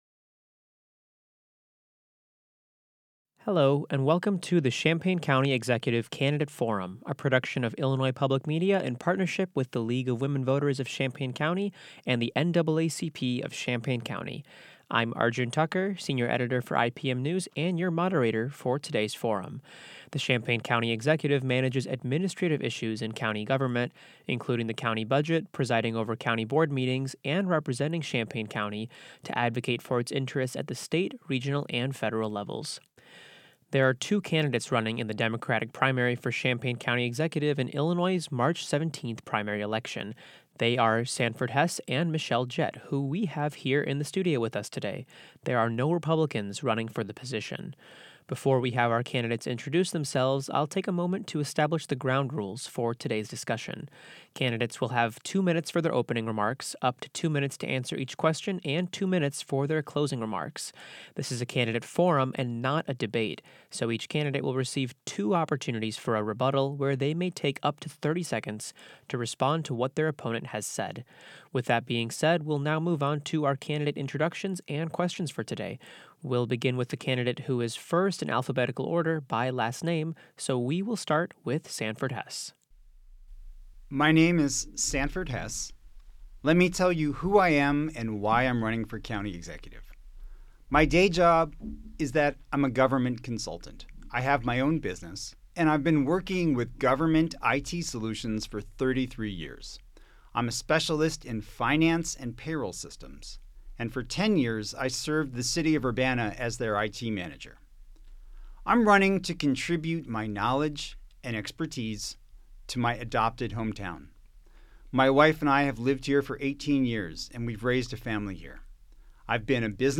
URBANA – The two Democrats running for Champaign County Executive shared their views on data centers, federal immigration enforcement and whether the position of county executive should be appointed or elected, at a candidate forum hosted by IPM News.